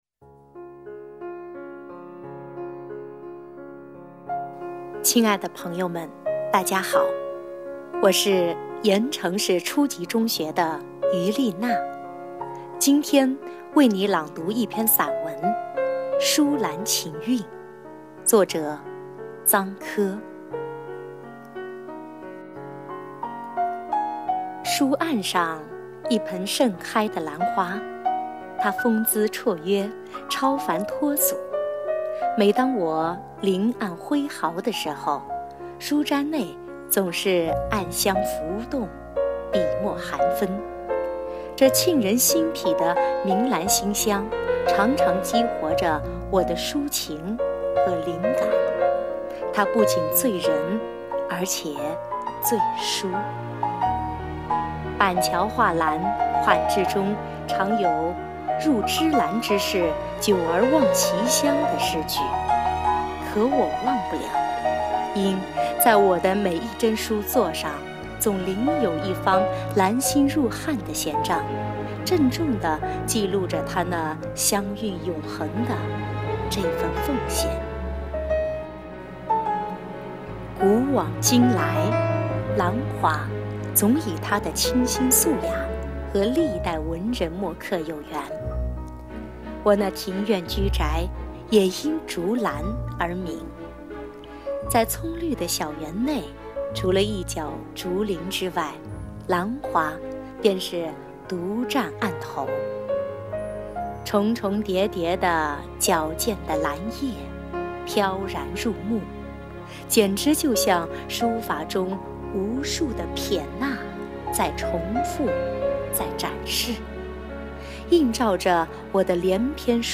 《书兰情韵》语音版
好听的声音，像人一样洋溢着典雅温婉